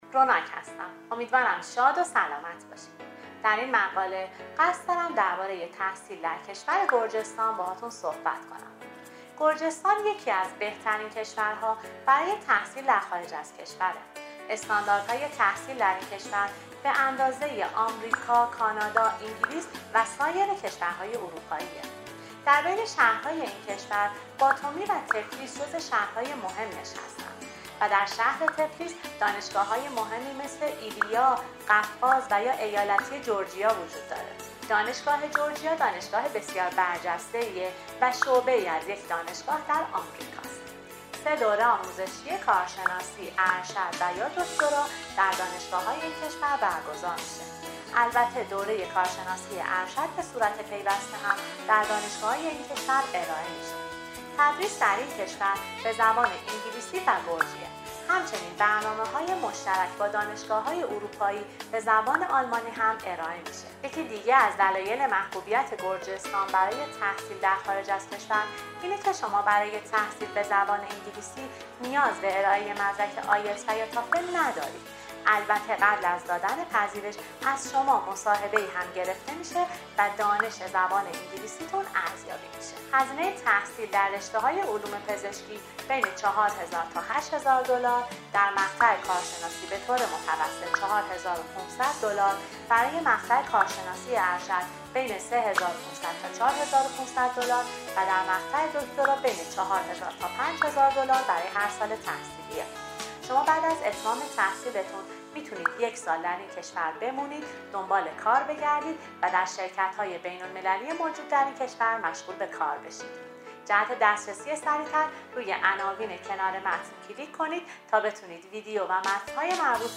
پادکست تحصیل در گرجستان